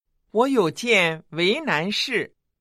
（ウオ　ヨウ　ジエン　ウェイナン　シー）